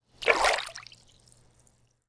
walk_water.wav